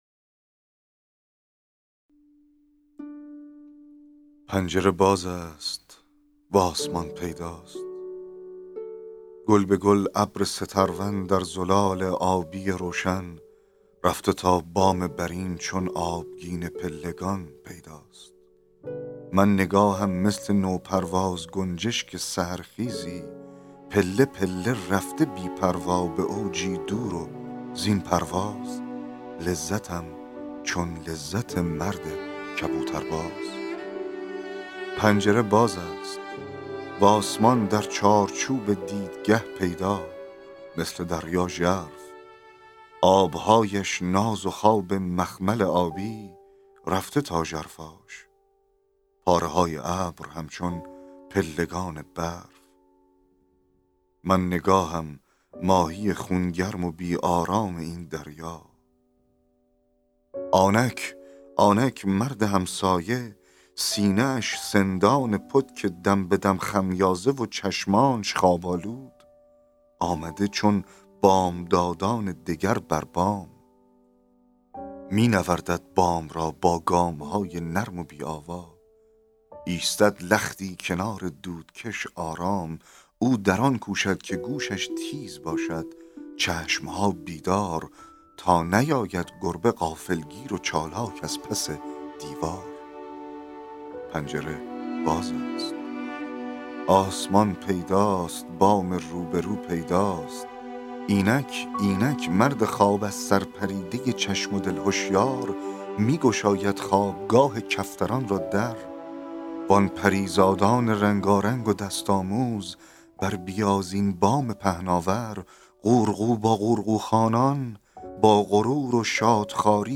فایل صوتی دکلمه شعر طلوع